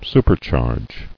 [su·per·charge]